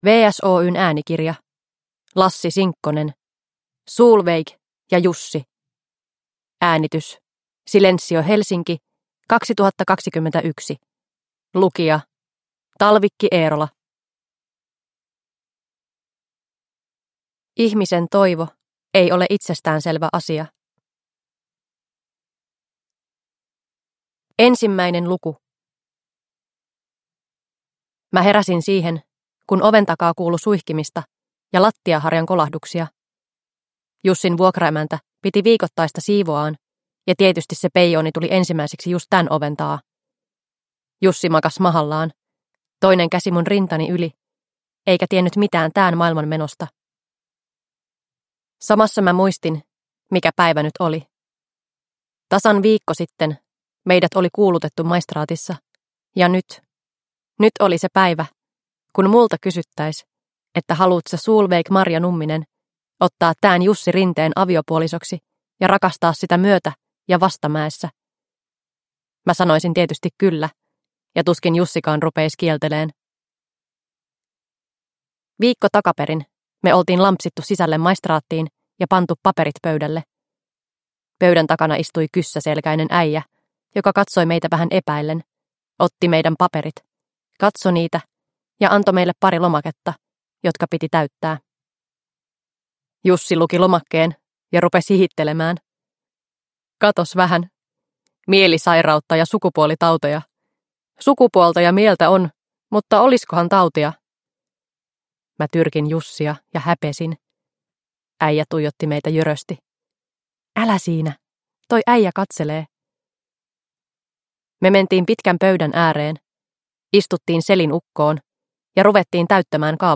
Solveig ja Jussi – Ljudbok – Laddas ner